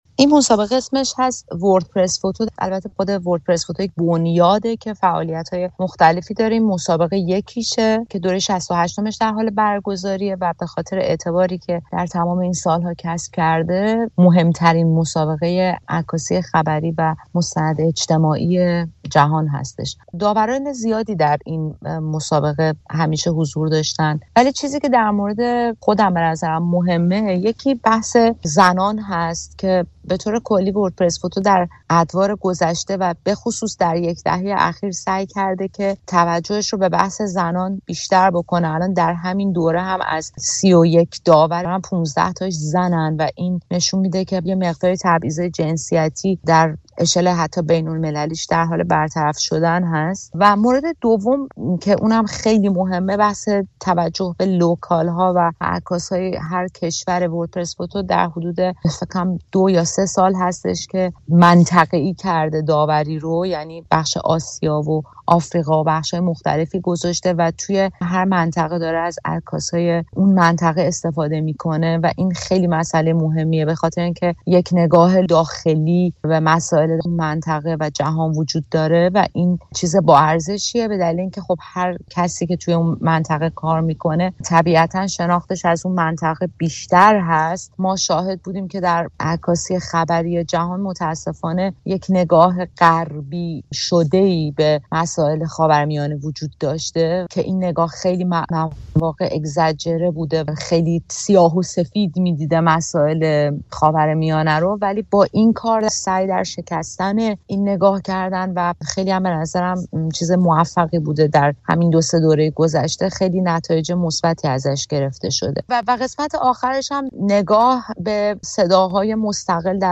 از محکومیت به پاکبانی تا داوری مسابقه جهانی عکاسی خبری؛ گفت‌وگو